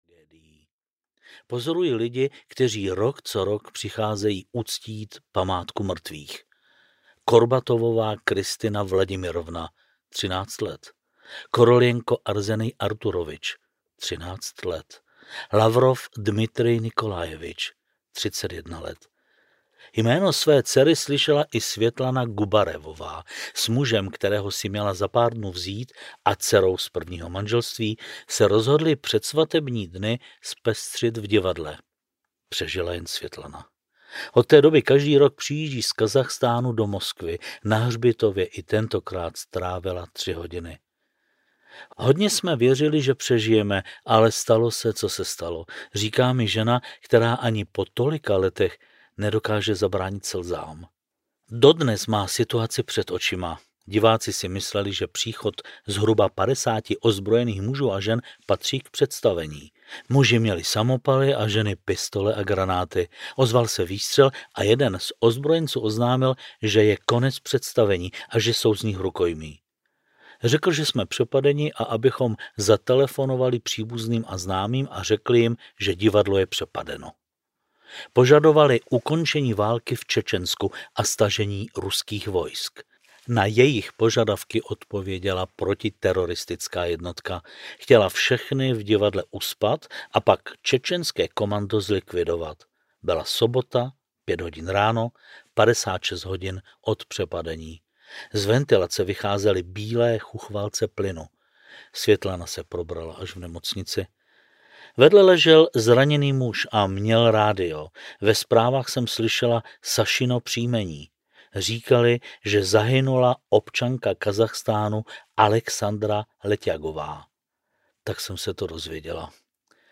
Audiokniha A odkud bych asi tak byl, kterou napsal a namluvil Miroslav Karas. Rusko očima zahraničního reportéra.
Ukázka z knihy
• InterpretMiroslav Karas